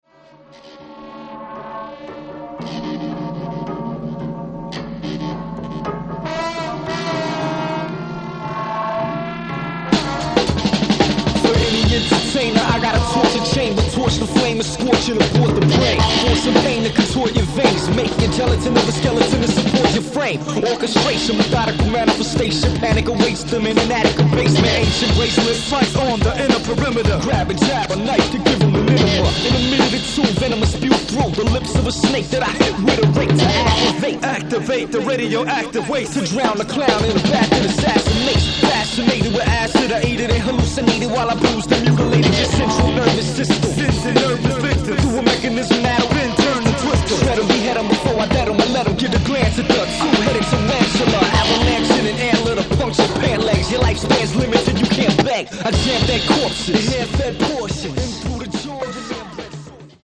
Psychedelic hip hop